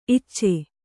♪ icce